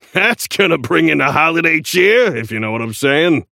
Shopkeeper voice line - That's gonna bring in the holiday cheer, if you know what I'm sayin'?